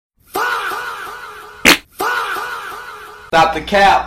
faaaa duck fart Sound Button: Unblocked Meme Soundboard